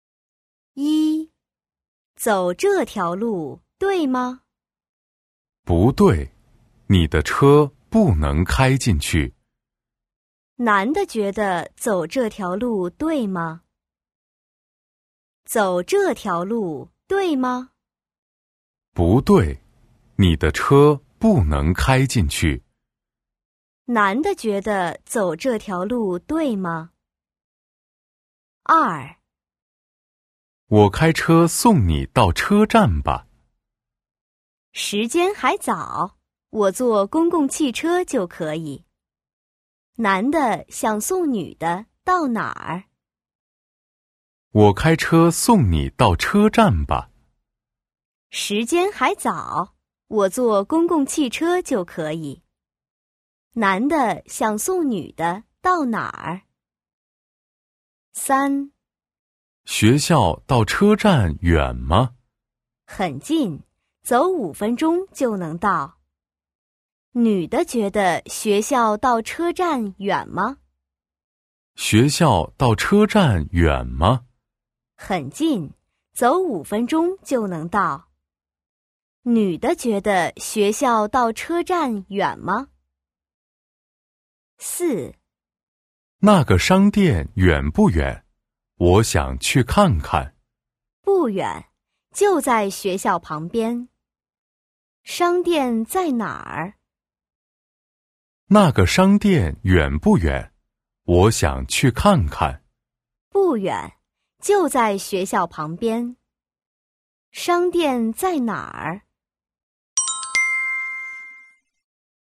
1. Kĩ năng nghe